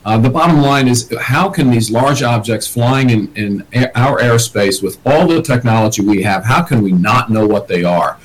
The Chair of the House Freedom Caucus on Capitol Hill, Andy Harris of Maryland says the Federal government needs to get and release answers about the hundreds of drone sightings around Washington and New Jersey. Harris spoke to Fox Business and said the government needs to respond quickly…